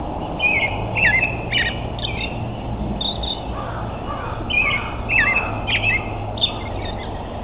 American Robin (Turdus migratorius)
Song with crow in background (59 KB)
robin1.au